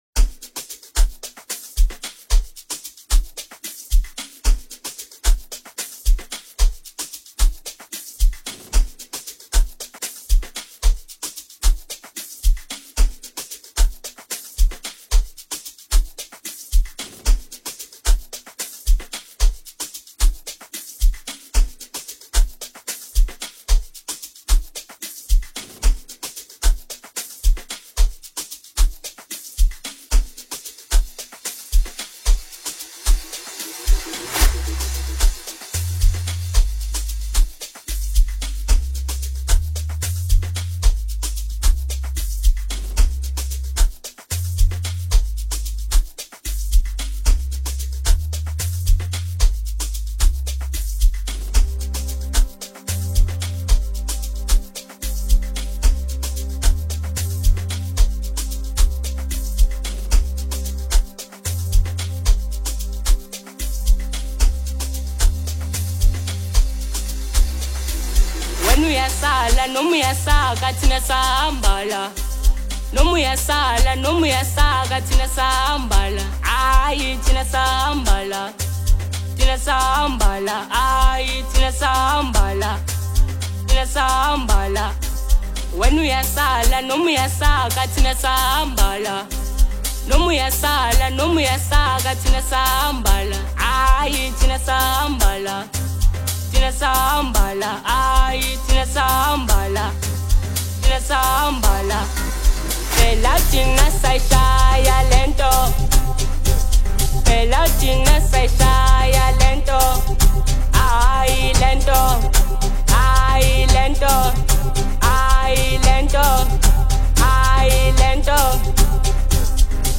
06:34 Genre : Amapiano Size